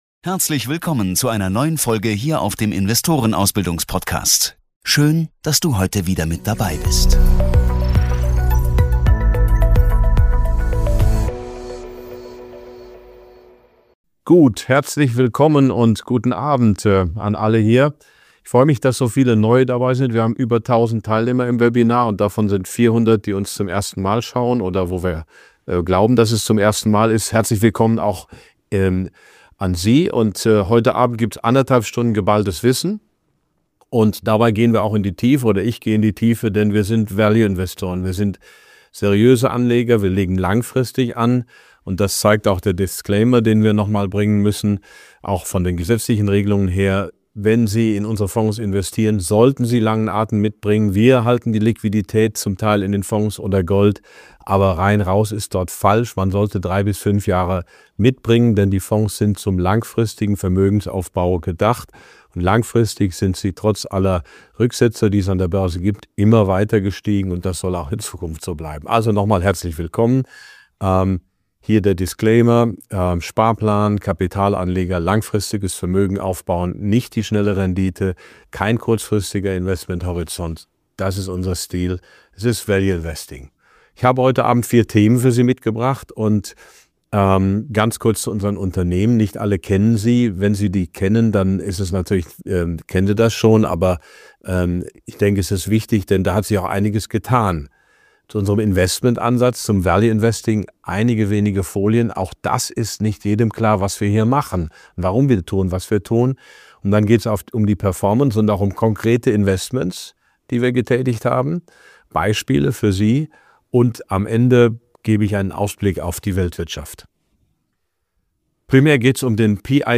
Beschreibung vor 1 Jahr Prof. Dr. Max Otte gibt im exklusiven Webinar einen Einblick in seine Investmentstrategie und die aktuelle Entwicklung seiner Fonds. Er erklärt die Grundprinzipien des Value Investing und analysiert die Performance seines Portfolios mit Berkshire Hathaway als Schlüsselposition.